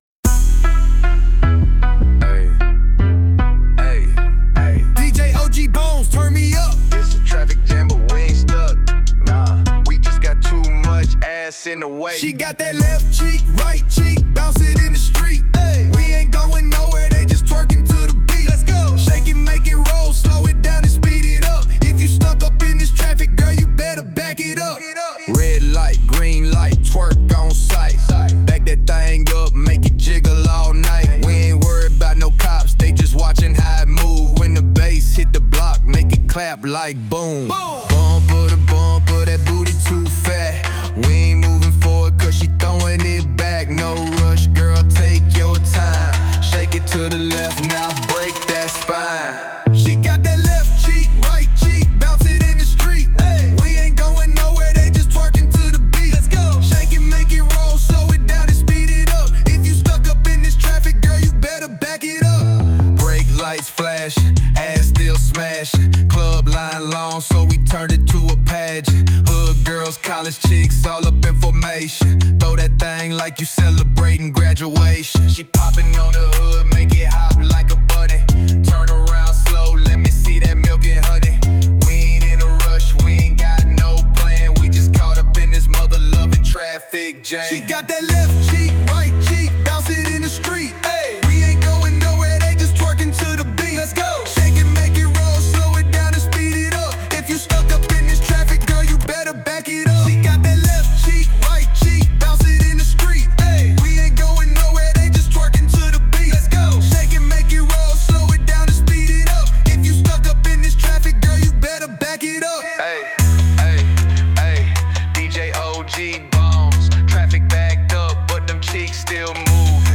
Hiphop
Description : twerk song and a comdey twerk song